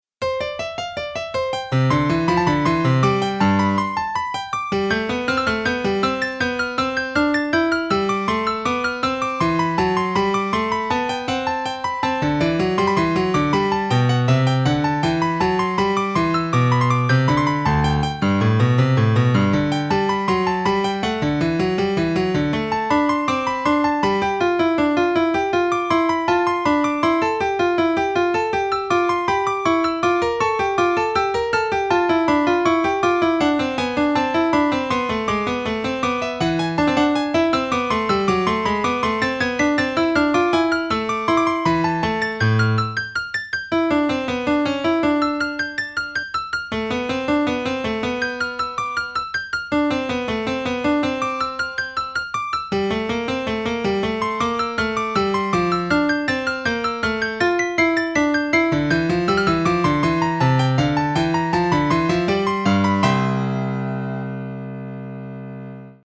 4分の4拍子。